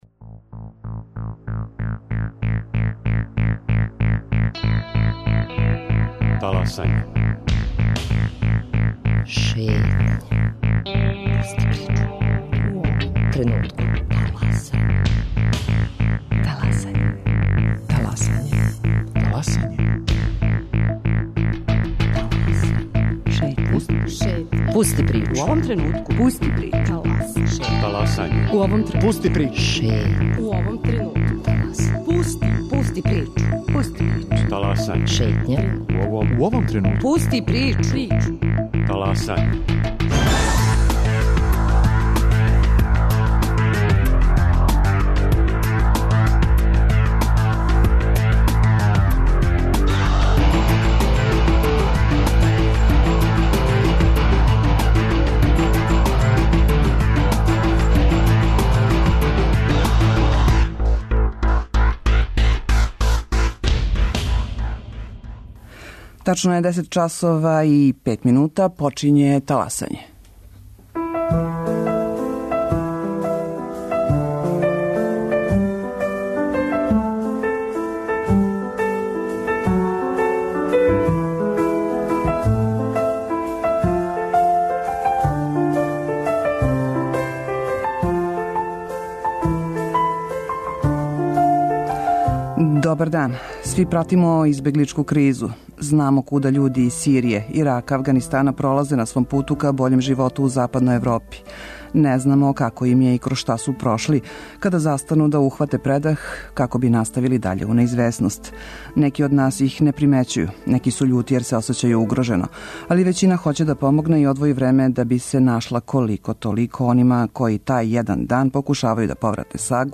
Гости Шетње су волонтери